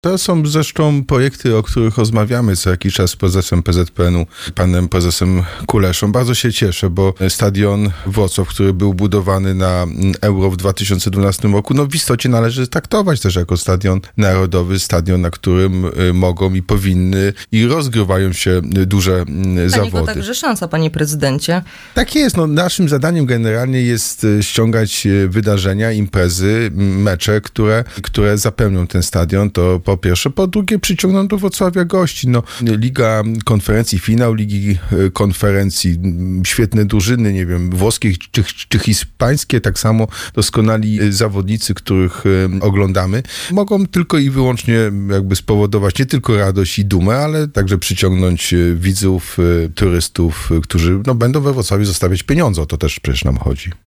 Przyznanie miastu organizacji meczu na naszej antenie skomentował prezydent Jacek Sutryk.